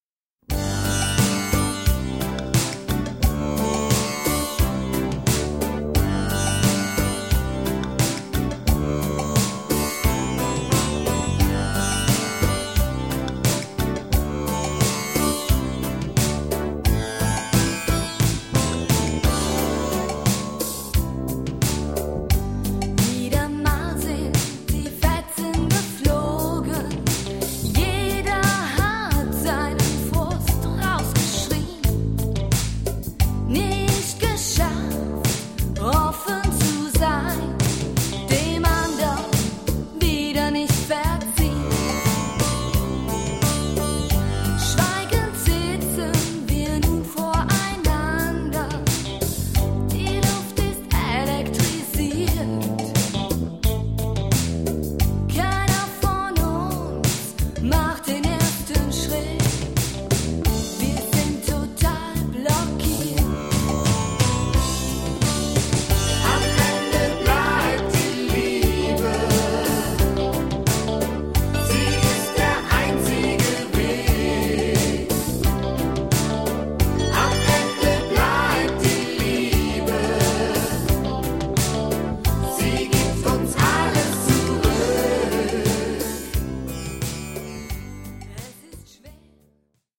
rockige Sounds